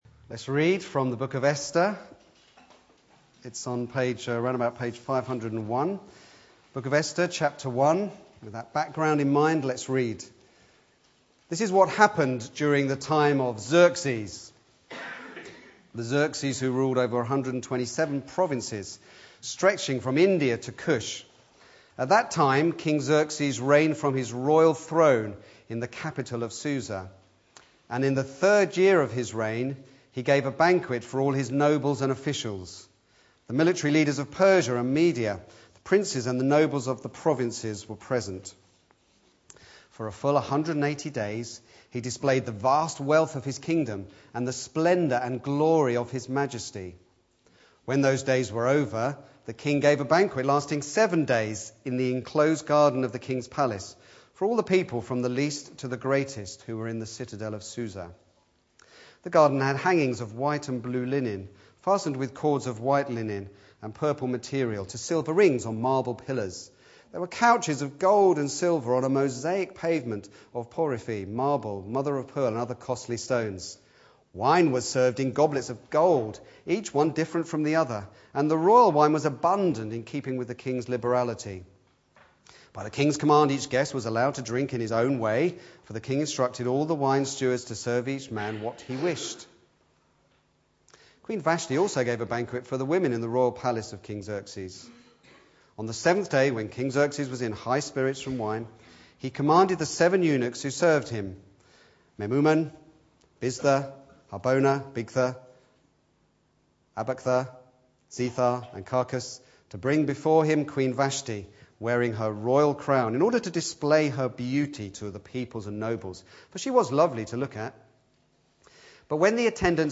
Back to Sermons For such a time as this